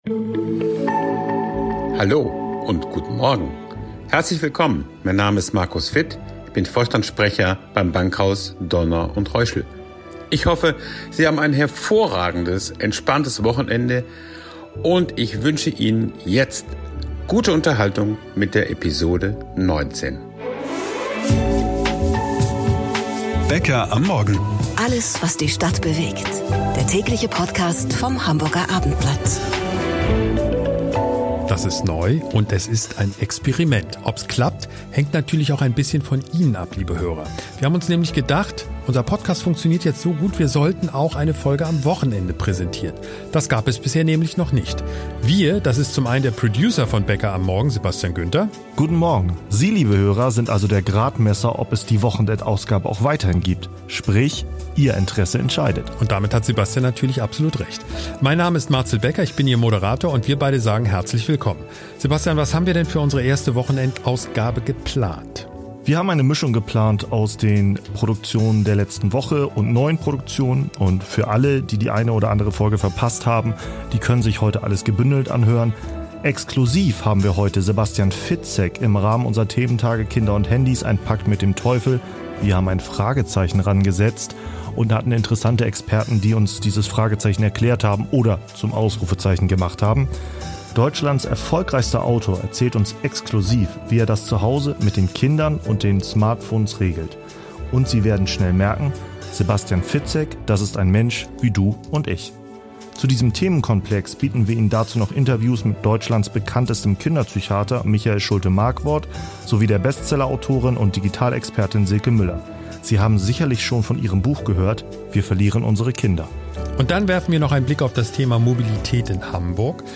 Die erste Wochenendfolge: Die spannensten Themen der letzten Woche und exklusive neue Interviews, Informationen und Hintergründe.